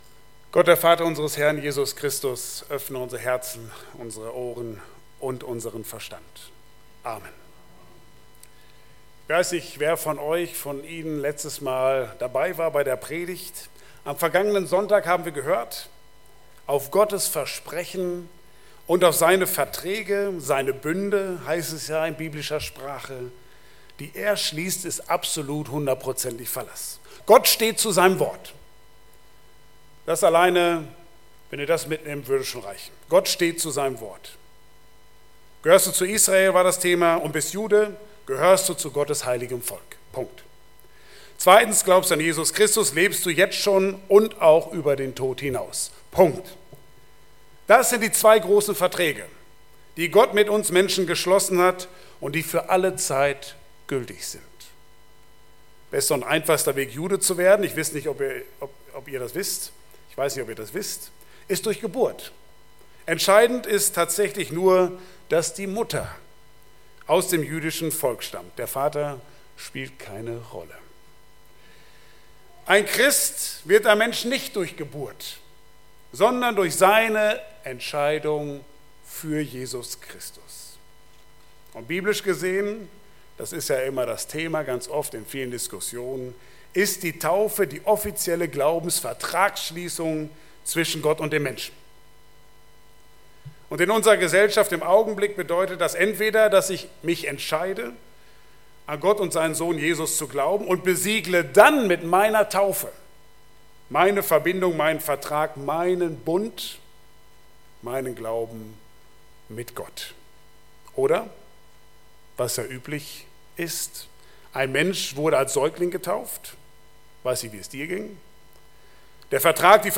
Passage: Hebräer 13,1-3 Dienstart: Gottesdienst Bible Text